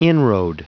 Prononciation du mot inroad en anglais (fichier audio)
Prononciation du mot : inroad